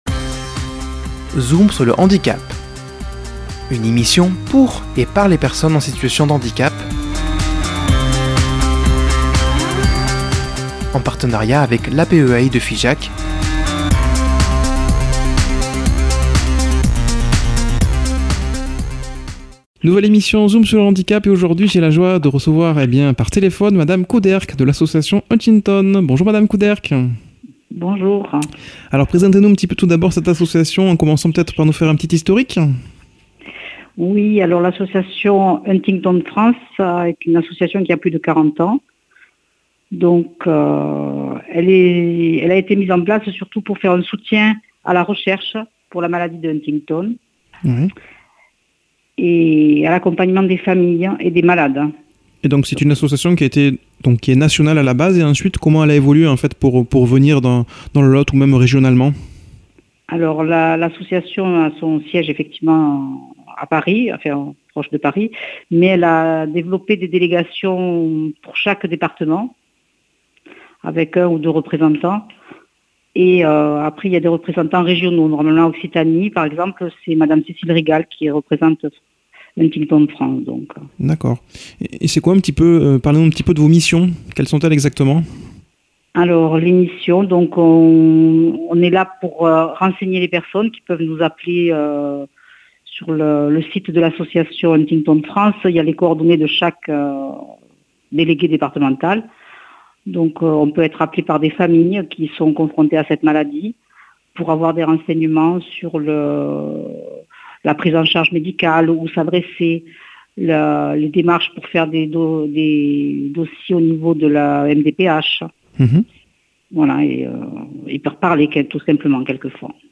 a comme invitée par téléphone